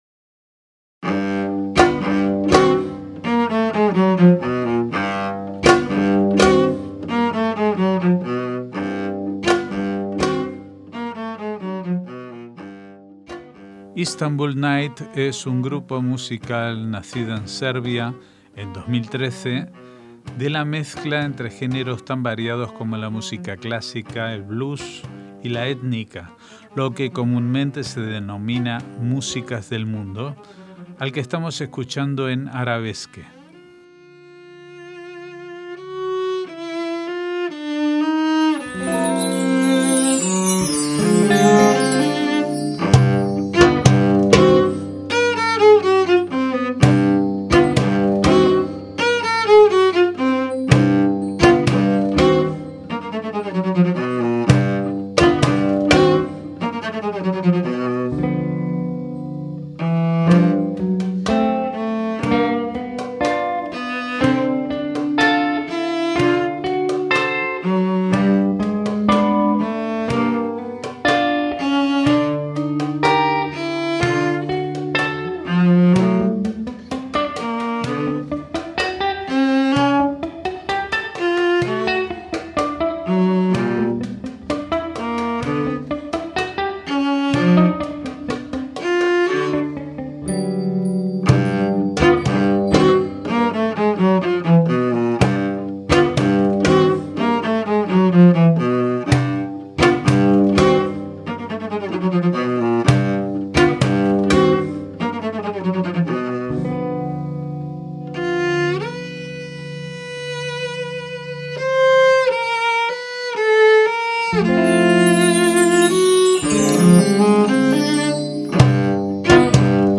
chelista
guitarrista
percusionista